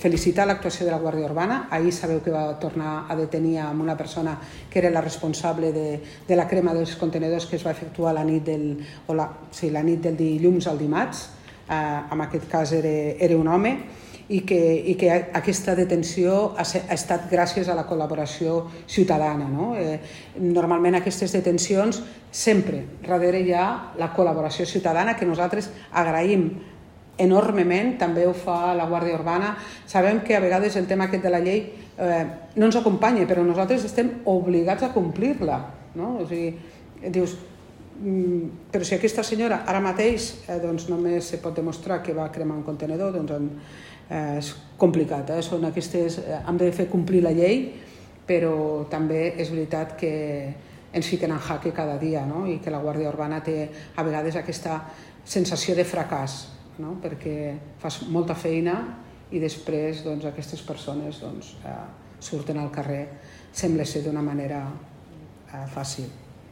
Tall de veu de l'alcaldessa accidental, Carme Valls, sobre la detenció del presumpte autor de la crema de sis contenidors